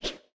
SFX_Weapon_Swoosh_04.wav